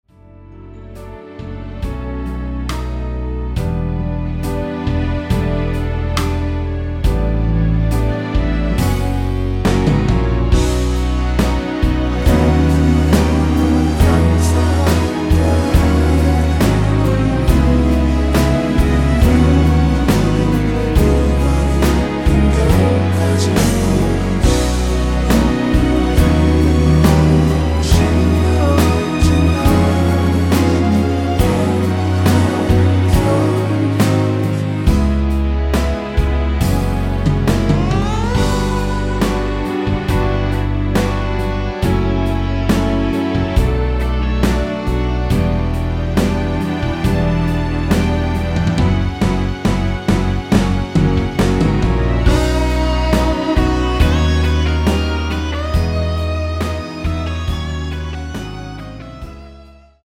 원키에서(-2)내린 코러스 포함된 MR 입니다.(미리듣기 참조)
앞부분30초, 뒷부분30초씩 편집해서 올려 드리고 있습니다.
중간에 음이 끈어지고 다시 나오는 이유는